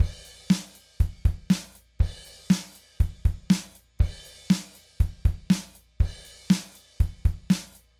繝ｻ繝吶Ο繧ｷ繝繧｣繧定ｪｿ謨ｴ縺励※縺縺ｪ縺繝峨Λ繝繝ｫ繝ｼ繝